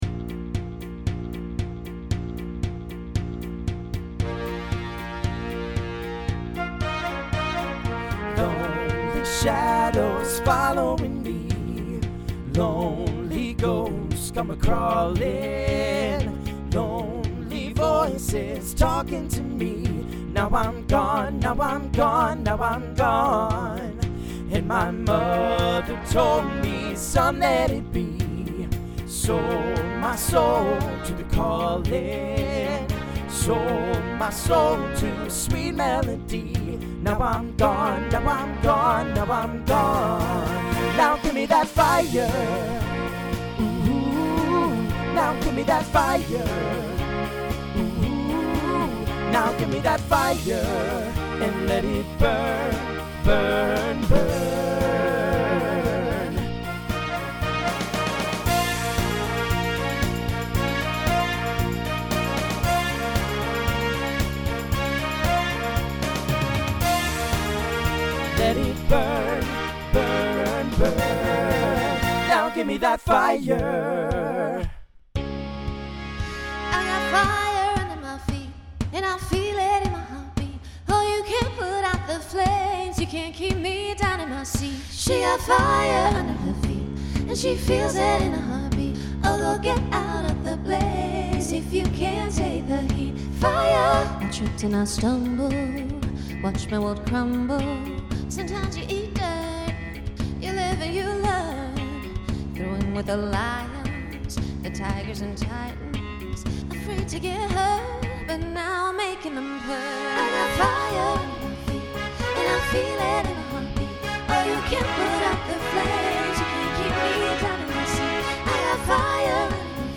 TTB/SSA
Voicing Mixed Instrumental combo Genre Rock